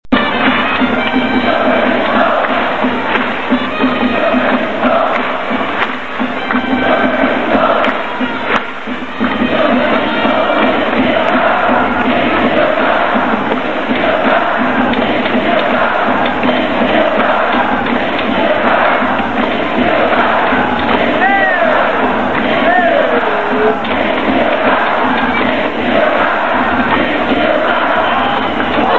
千葉ロッテマリーンズ選手応援歌